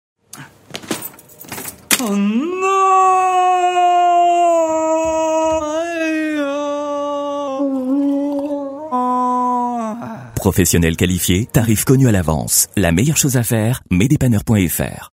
VOIX OFF SIGNATURE
6. ENGIE droit, tonique